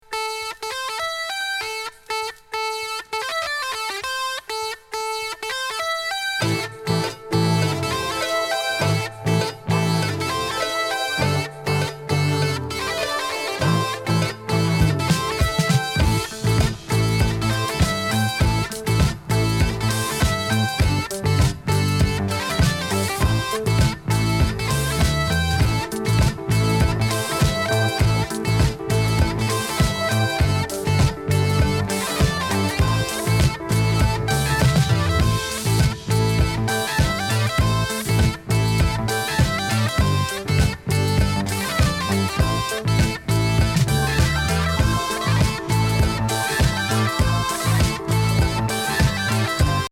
飛翔エレクトリック・プログレ